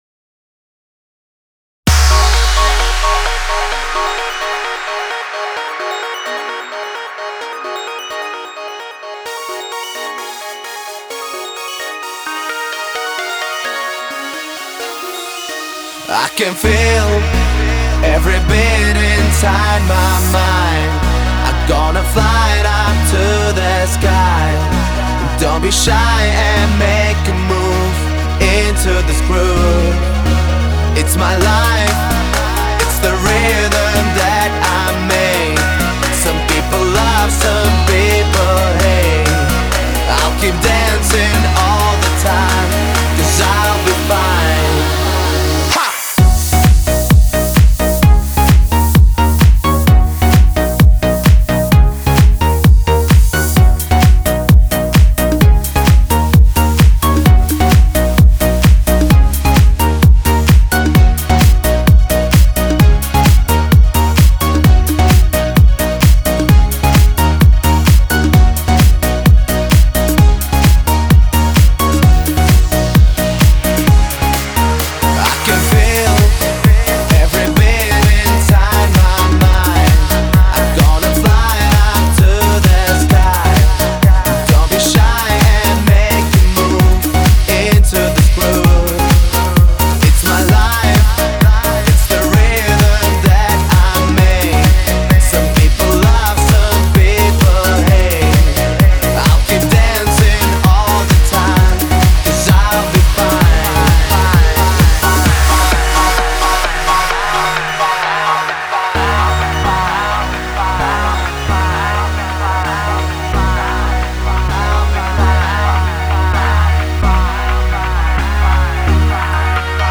Таги: DJ